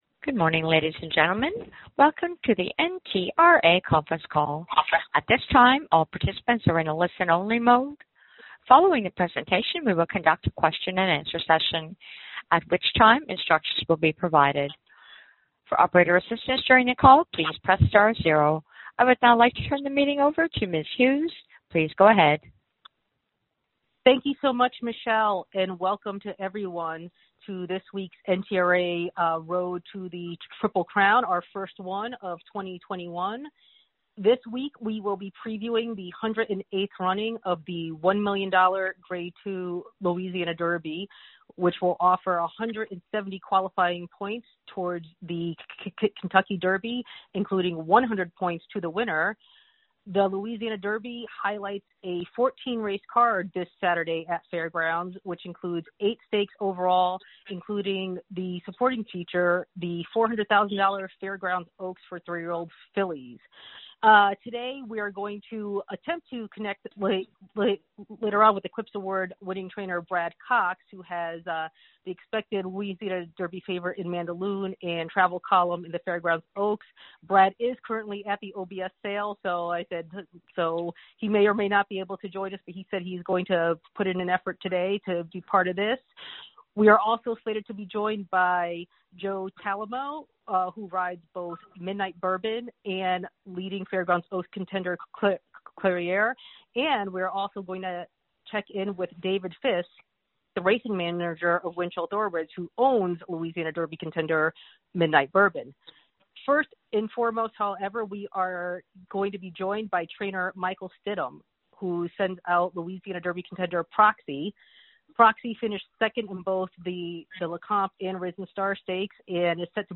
National Media Teleconference
Welcome to the NTRA Conference Call.